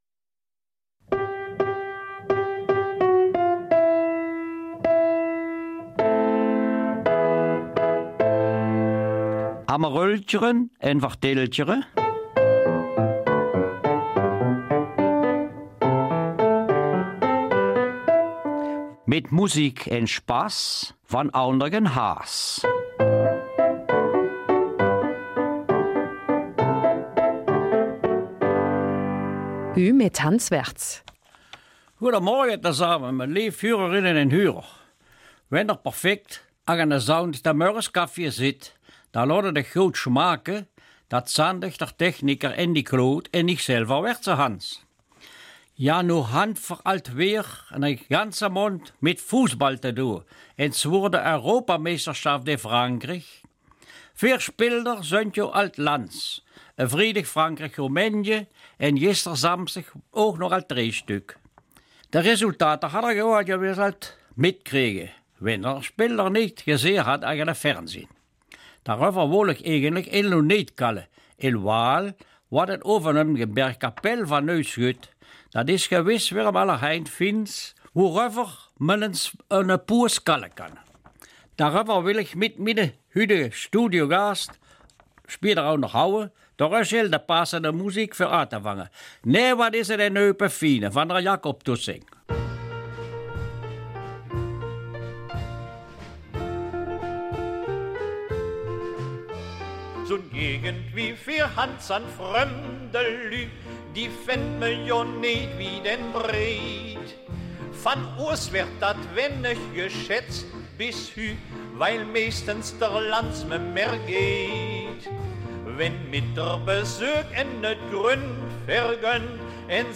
Eupener Mundart: Neues aus dem Bergviertel
Eupener Mundartsendung am Sonntag, dem 12. Juni, von 9 bis 9:30 Uhrauf BRF2, wiederholt ab 19 Uhr und nachzuhören hier.